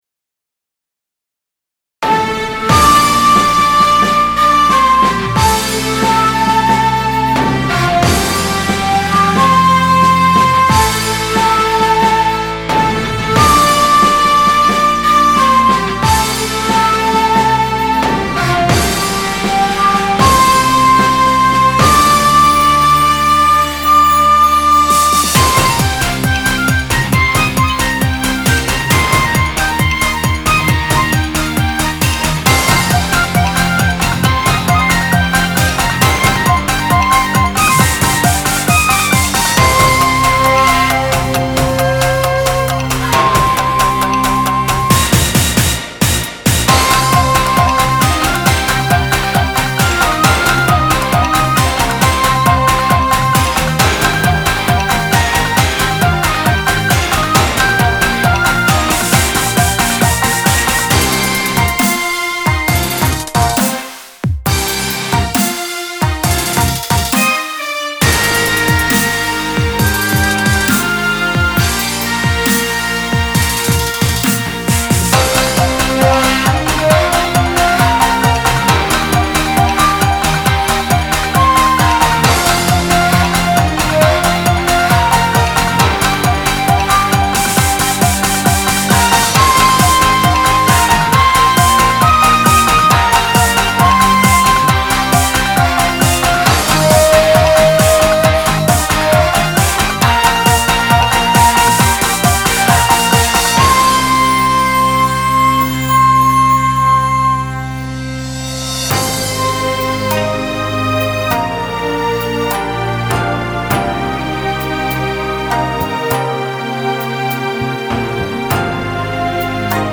ＯＰ　　 　0：00　〜　0：24　　｜　　オープニング（静）
第一部　0：25　〜　1：54　　｜　　地域の歌を織り交ぜた、元気良いパート（動）
第二部　1：54　〜　2：45　　｜　　前後半で２部構成の静パート
第三部　2：46　〜　4：14　　｜　　１部と同じく、地域の歌を織り交ぜた勢いのよいパート（動）
バスドラムのリズムに注目して頂くと伝わりやすいですが、
テンポ通り４つ打ちのパートと、テンポの半分（〜２拍）程度のリズムのパートを交互に切り替えるようにしていますので
テンポが速く、「動」パートの時間も長いため、勢いのある曲に慣れているチーム様に適しています。
(前半・後半ともにソーラン節)   10,000円